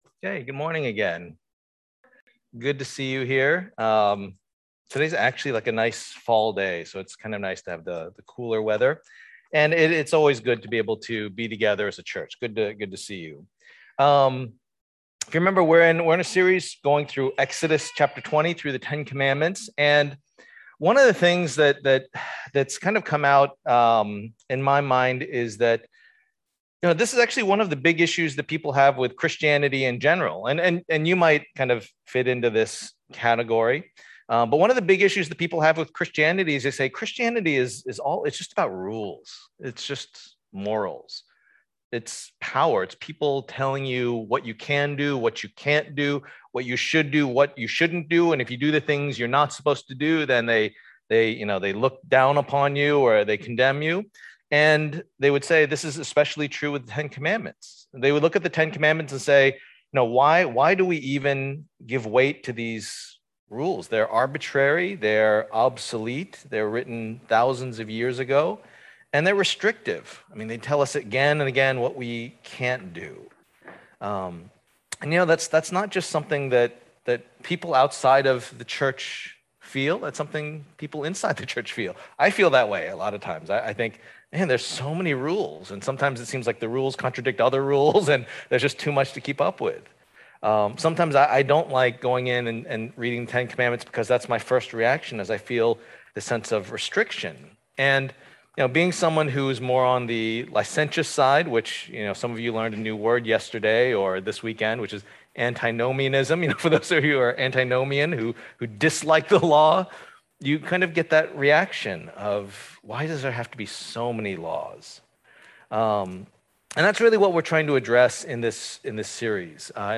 Passage: Exodus 20:8-11 Service Type: Lord's Day